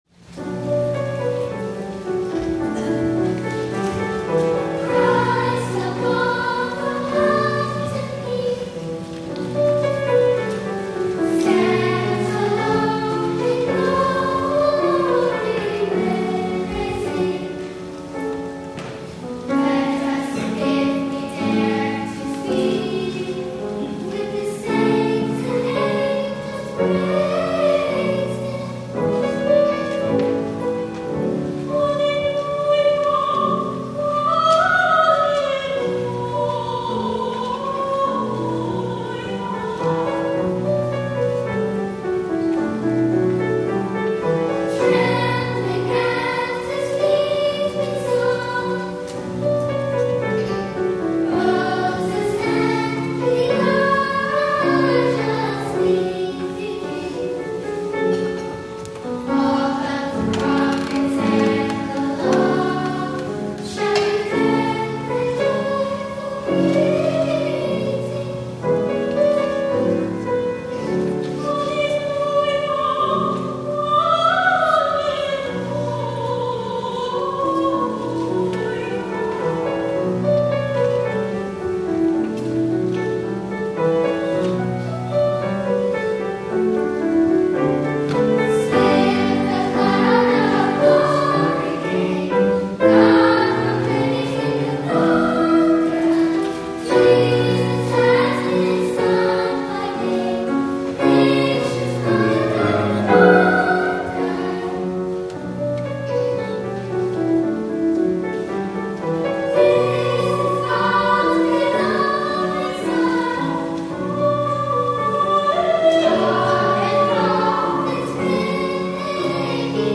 THE OFFERTORY
Youth Ensemble
solo descant